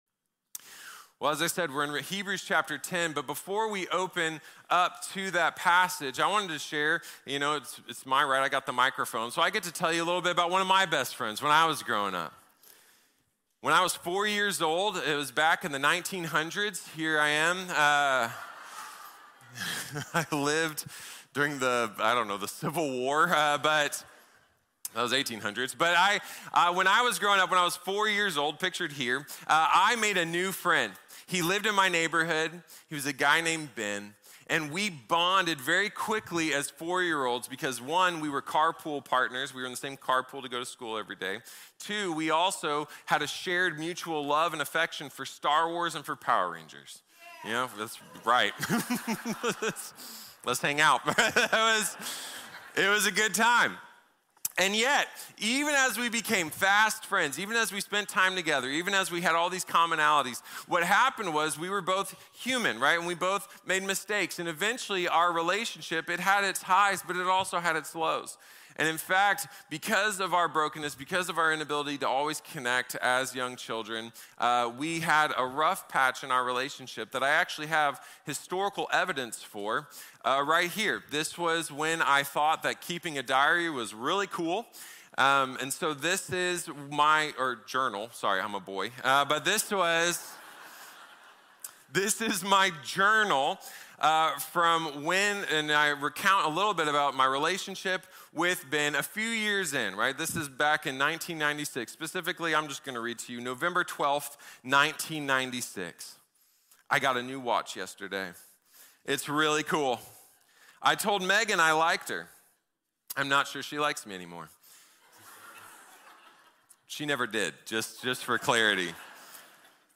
Invest in Community | Sermon | Grace Bible Church